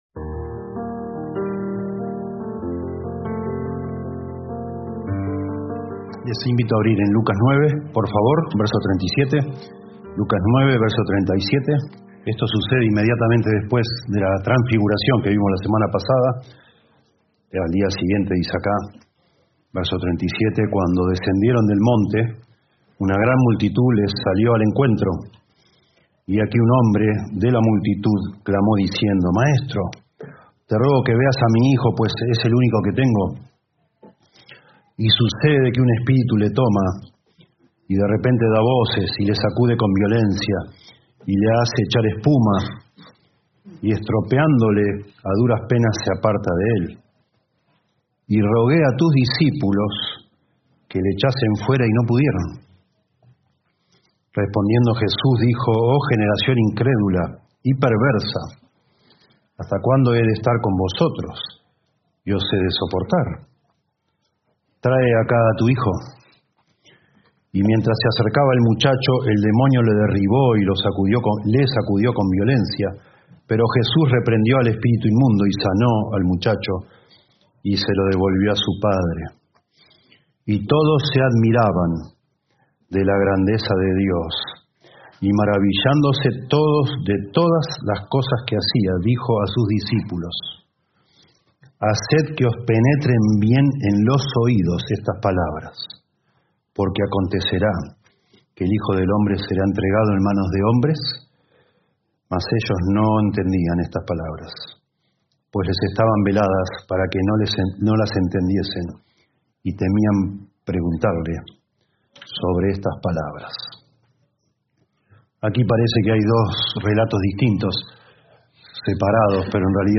Reina-Valera 1960 (RVR1960) Video del Sermón Audio del Sermón Descargar audio Temas: